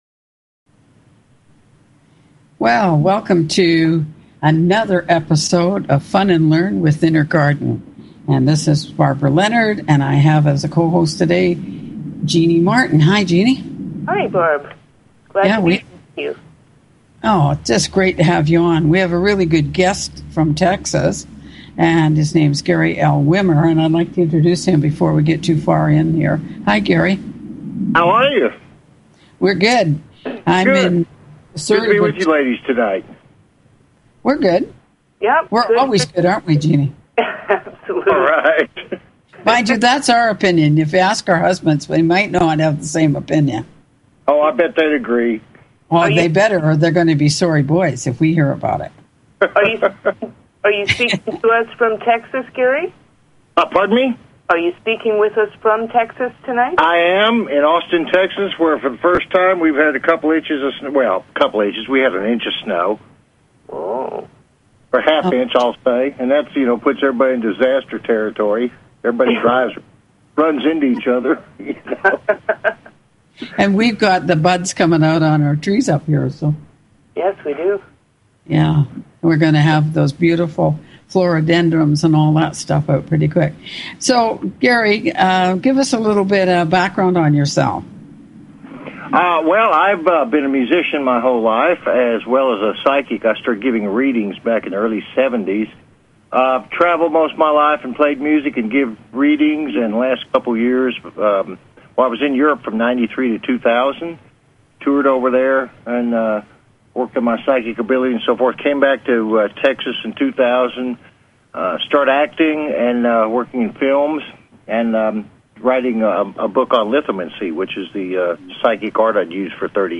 Talk Show Episode, Audio Podcast, Fun_and_Learn_with_Inner_Garden and Courtesy of BBS Radio on , show guests , about , categorized as